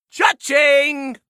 cha-ching